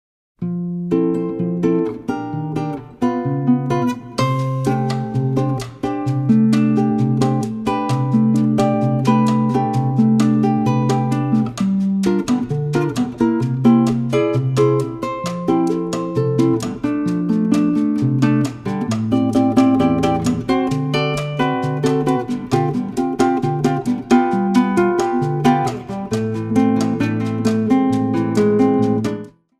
Guitar
Percussions